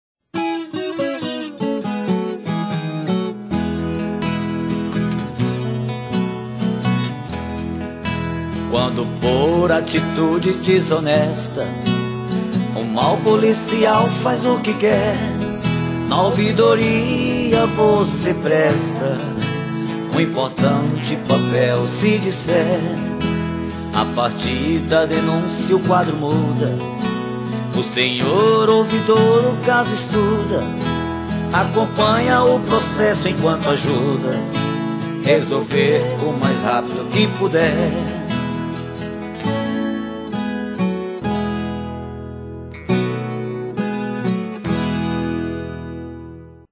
Faixa 12 - Spot Raiz 4